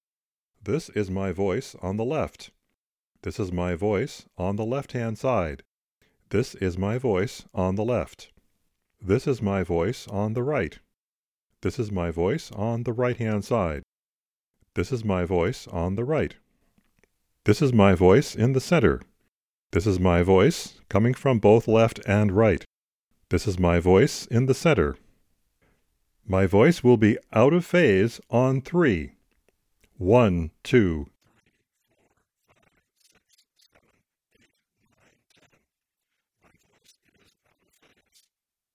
Here is a stereo reference track.